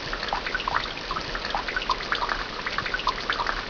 rain.wav